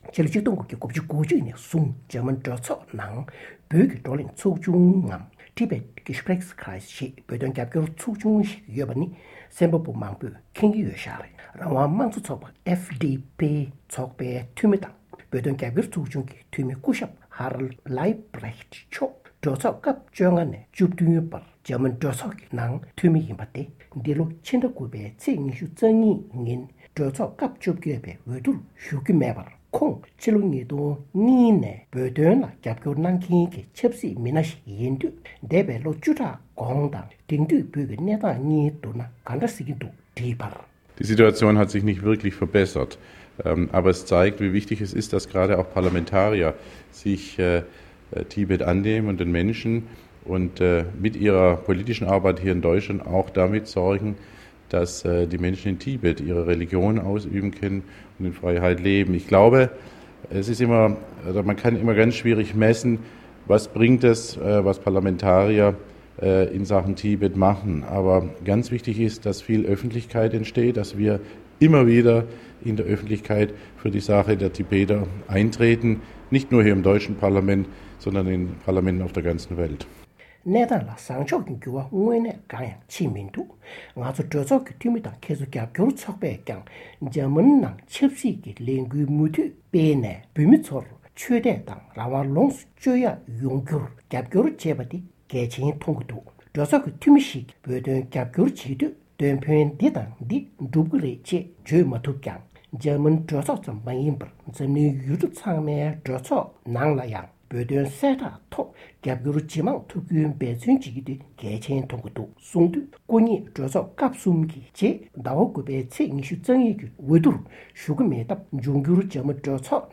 དམིགས་བསལ་བཅར་འདྲི།